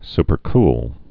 (spər-kl)